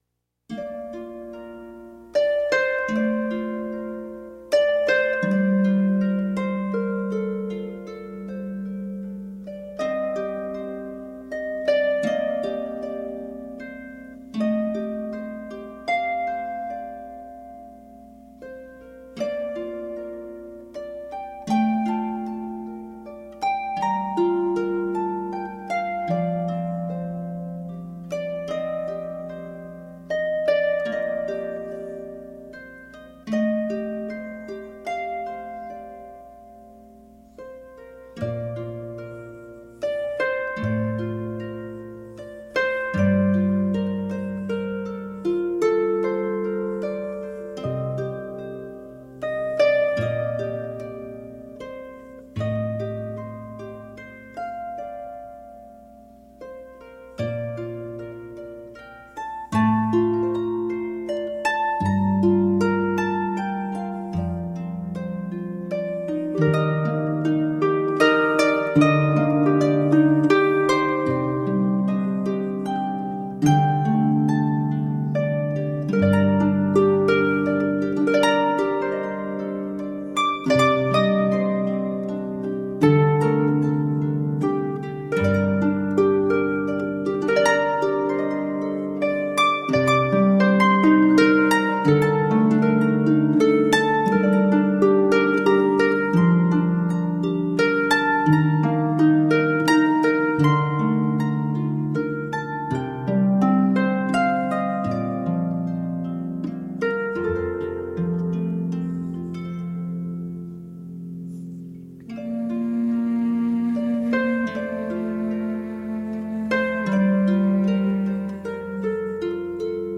Tagged as: World, New Age, Renaissance, Celtic, Harp